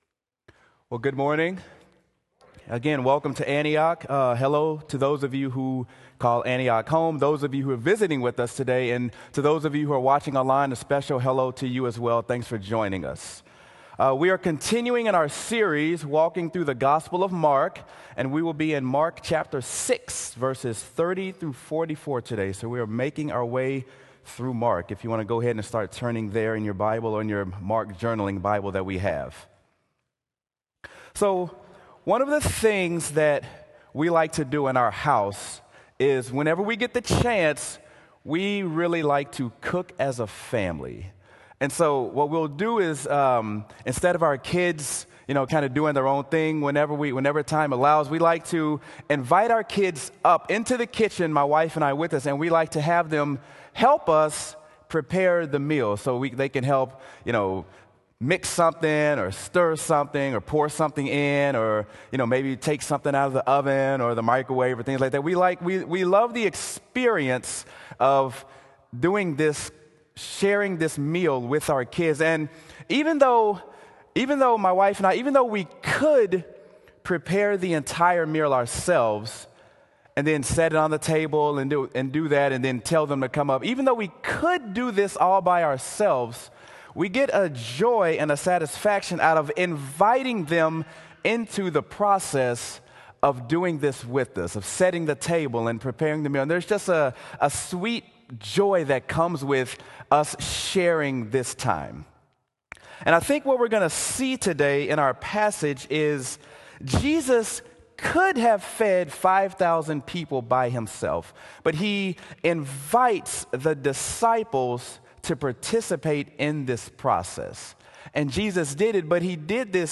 Sermon: Mark: A Satisfied Appetite | Antioch Community Church - Minneapolis
sermon-mark-a-satisfied-appetite.m4a